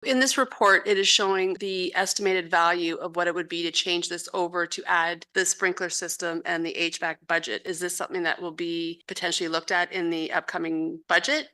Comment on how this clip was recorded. A hefty price tag of nearly half a million dollars is what’s needed to bring the Stephen Arena up to code compliance – that’s according to an architectural report presented to South Huron Council on Monday (June 17th).